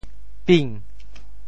ping2.mp3